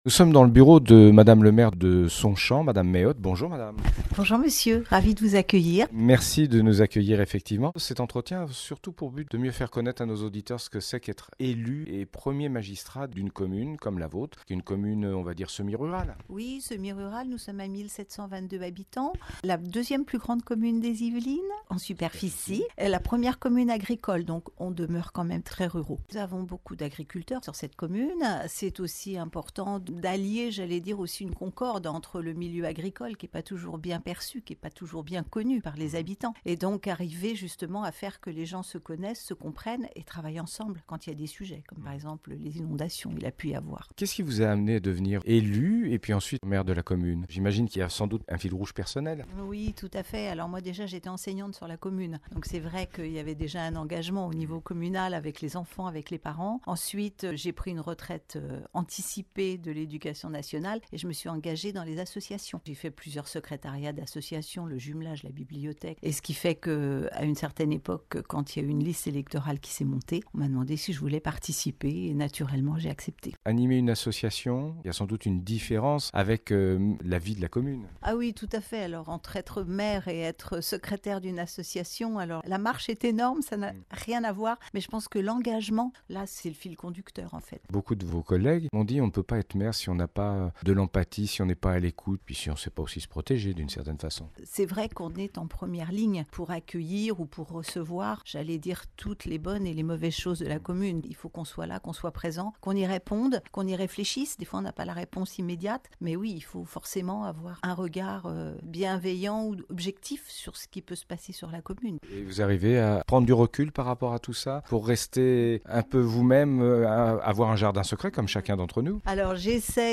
Interview de Madame May Ott Maire de Sonchamp - Radio RVE